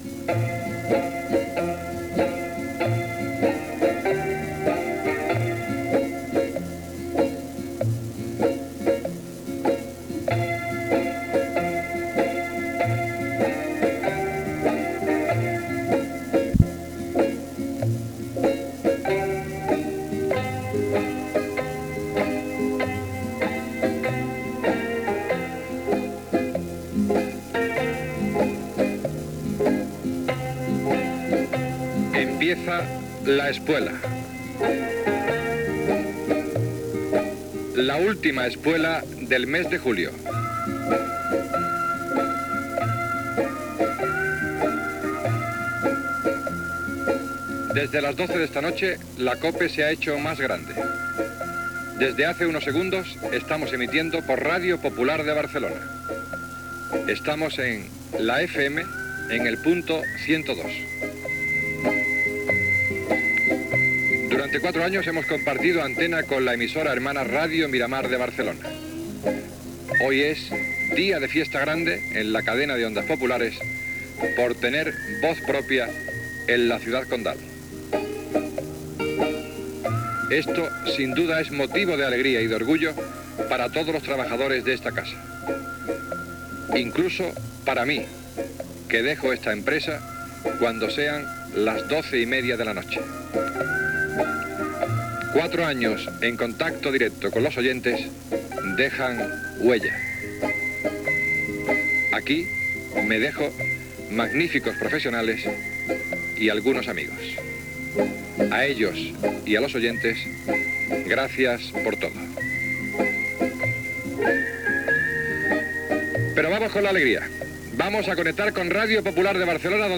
Gènere radiofònic Informatiu
Nit de la inauguració oficial de Ràdio Popular de Barcelona.